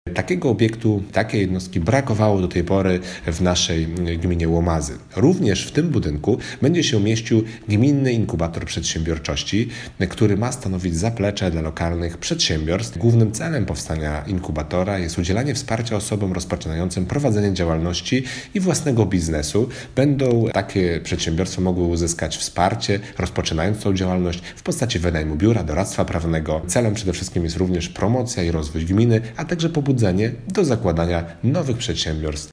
– W wyremontowanym budynku po kółkach rolniczych wsparcie znajdą też lokalni przedsiębiorcy – mówi wójt gminy Łomazy, Jerzy Czyżewski.